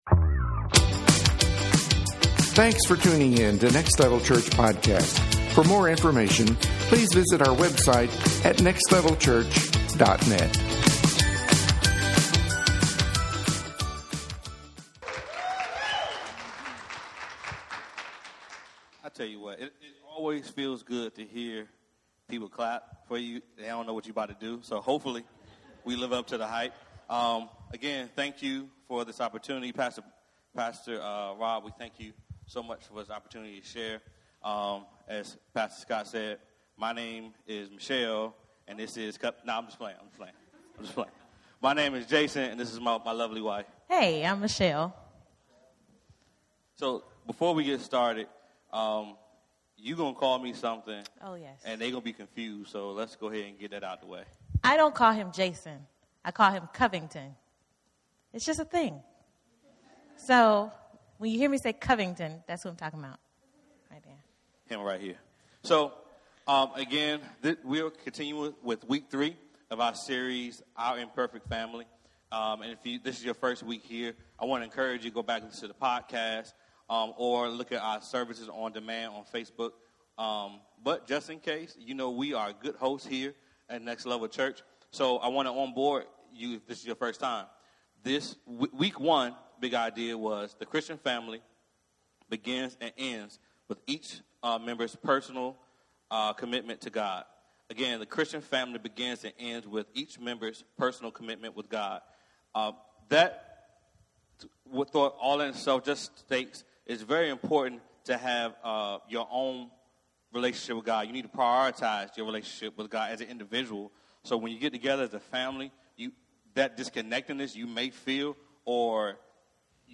Our Imperfect Family Service Type: Sunday Morning Watch « Our Imperfect Family